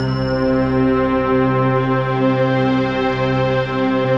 SI1 BELLS04L.wav